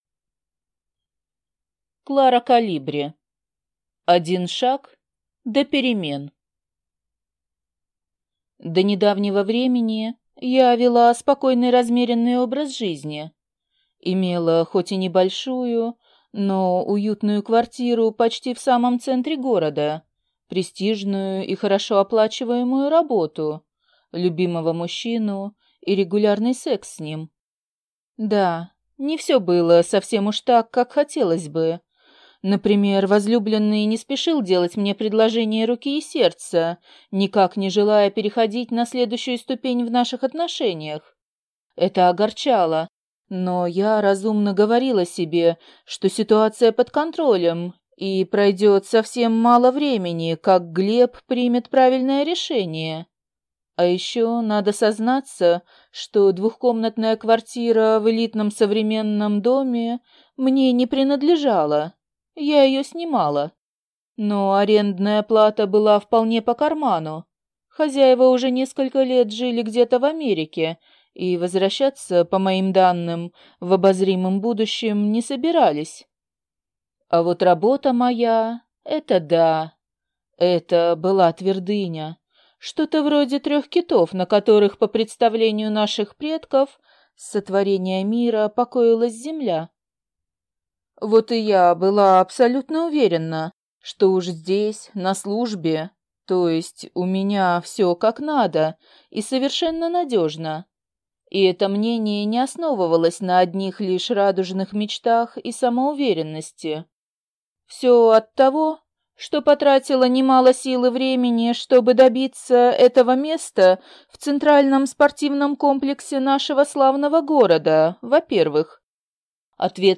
Аудиокнига Один шаг до перемен | Библиотека аудиокниг